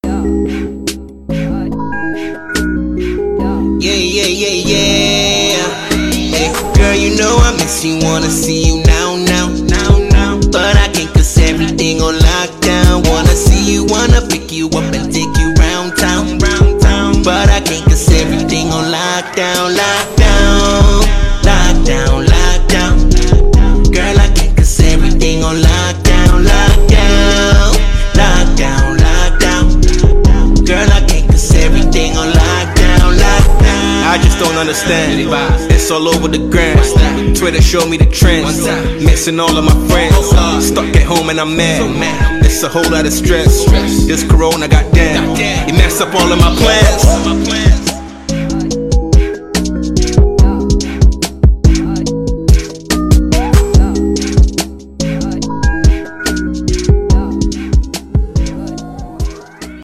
Ghanaian award winning singer and producer